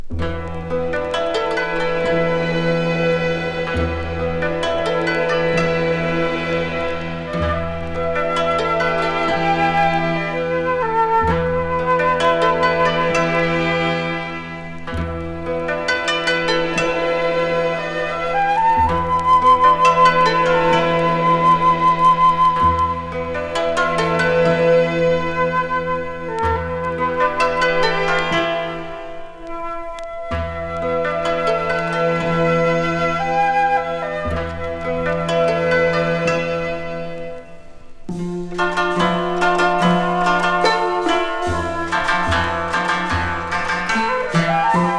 日本の楽器から琴や和太鼓などを織り交ぜて真新しい和洋折衷サウンドを展開したことでも知られる彼。
Jazz, World　USA　12inchレコード　33rpm　Stereo
未開封品のため、試聴音源は同内容日本盤の音源を使用。